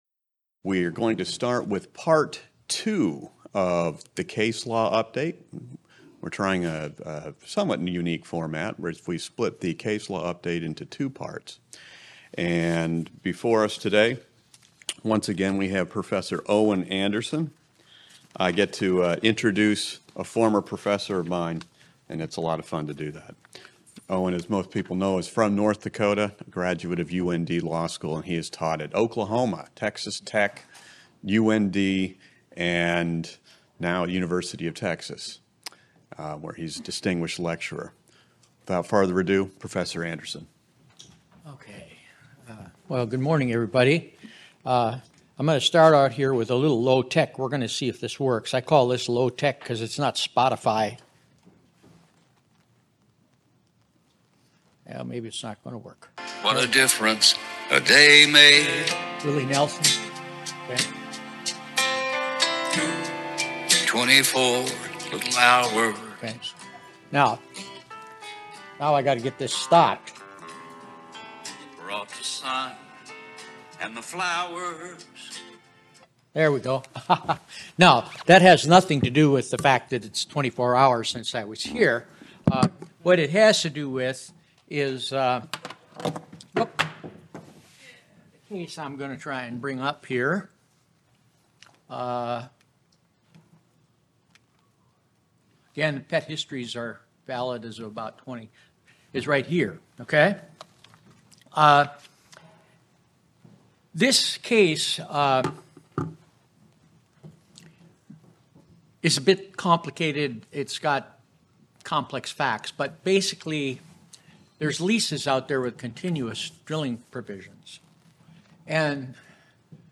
Originally presented: Apr 2024 Oil, Gas and Mineral Law Institute